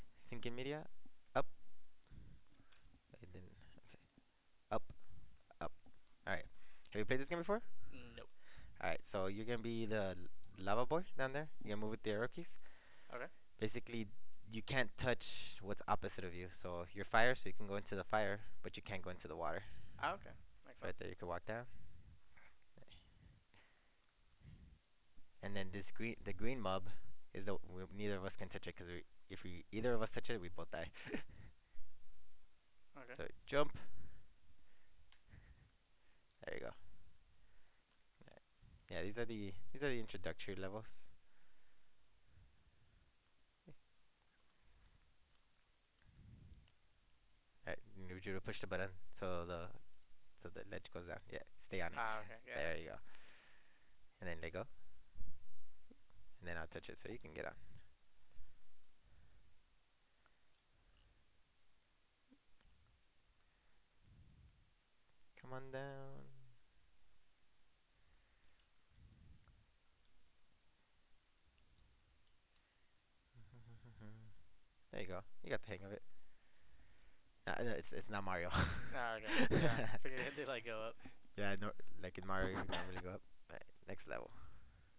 Creakiness, Breathiness, and Nasality Contribute to the Perceived Suitability of Synthesized Speech in a Pragmatically-Rich Domain
Original and Manipulated Audio Examples: Clips (842KB), Description Appendix: Audio of the Examples (1:25)
Participants used the voice conversion tool VoiceQualityVC to make fine-grained adjustments to parameters affecting perceived voice quality and nasality. Working with utterances taken from a corpus of collaborative gameplay, they were able to modify synthesized speech to better match how they thought it should sound.